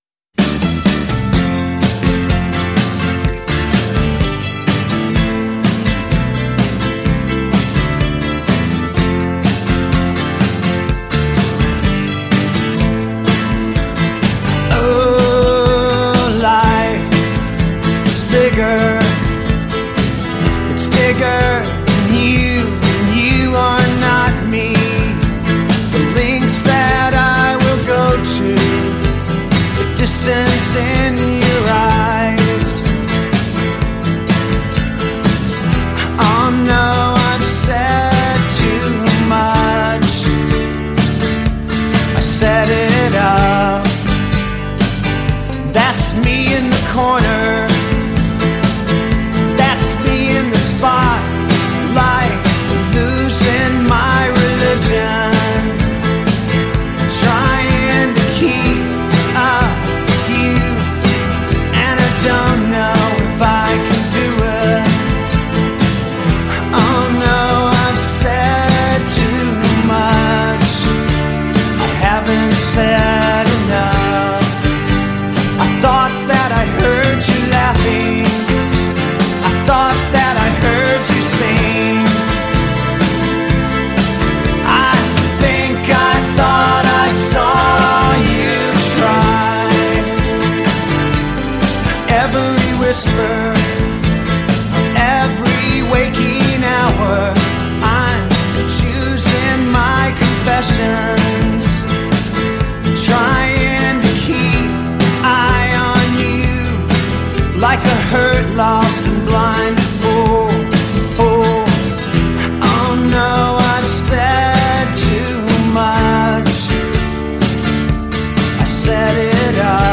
Жанр: Alternative